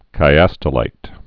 (kī-ăstə-līt)